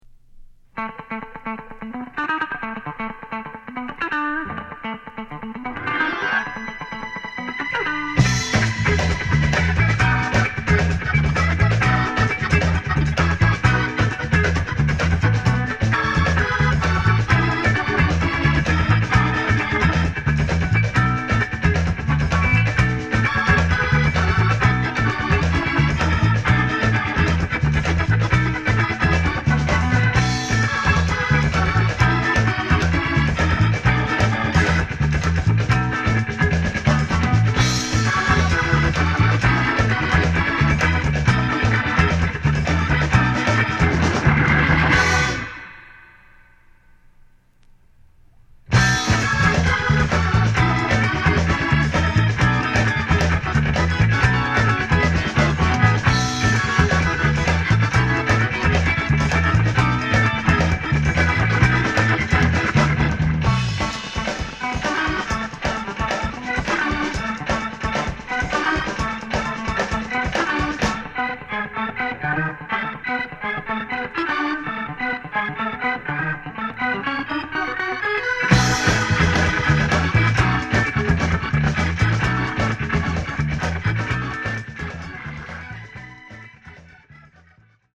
Genre: Soul Instrumentals
Great breaks and extremely funky beats!